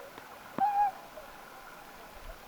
tuollainen laulujoutsenlinnun ääni
Ilmeisesti on itäisten laulujoutsenten ääntely,
hieman erilaista kuin täällä pesivien lintujen ääntely.
tuollainen_laulujoutsenlinnun_aani.mp3